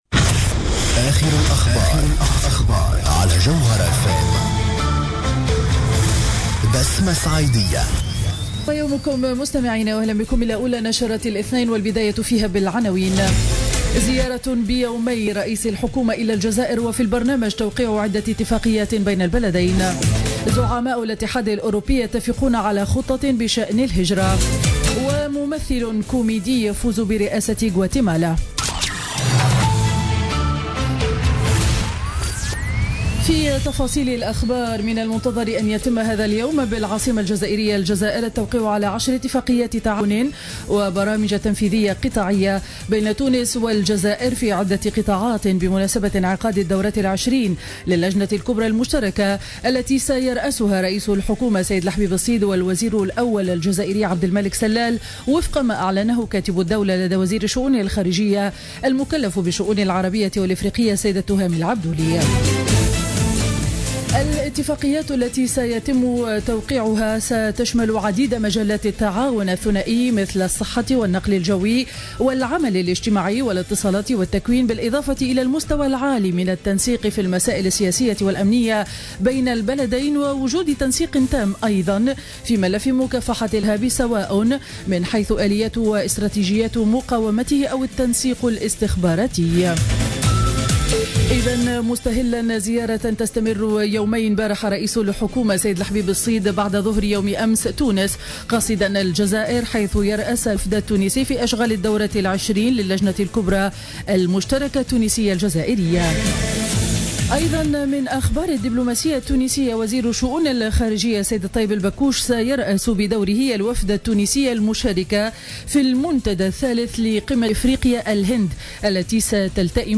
نشرة أخبار السابعة صباحا ليوم الاثنين 26 أكتوبر 2015